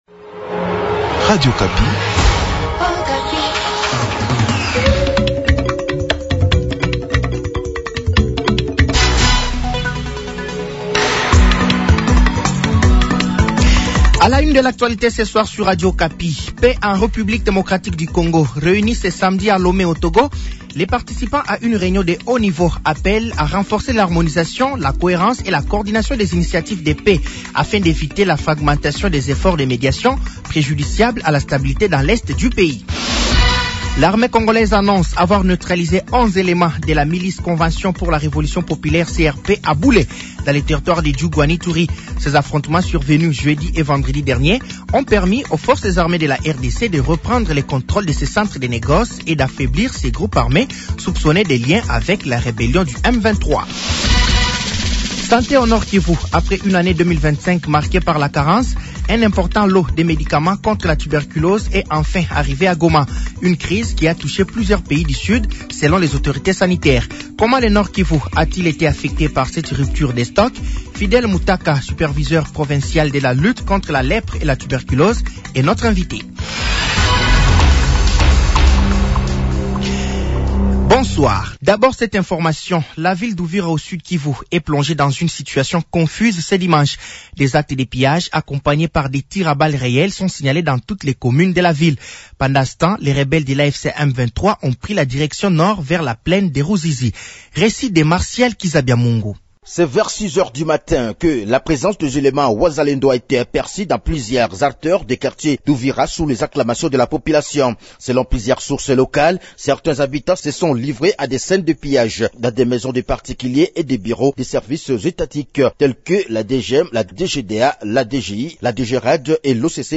Journal français de 18h de ce dimanche 18 janvier 2026